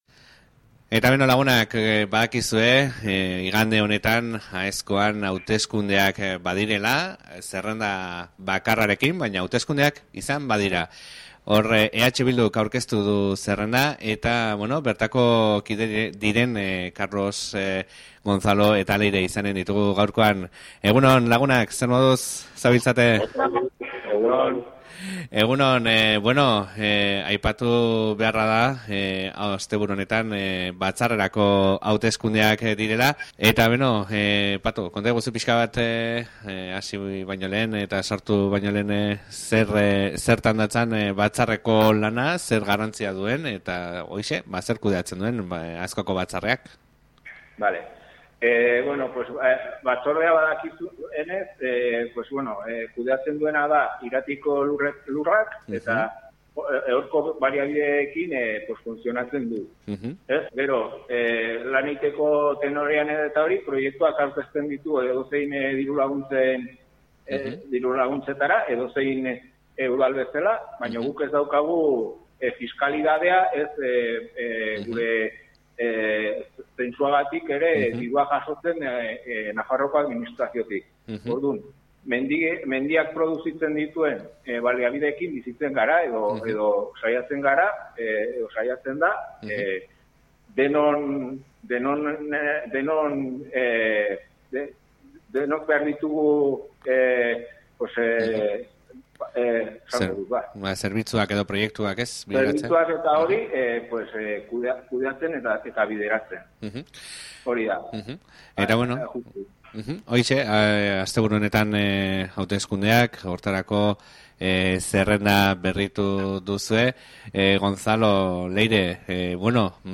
Aezkoako Batzarrerako bozak igandean, EH Bildu Aezkoako kideekin solasaldia | Irati Irratia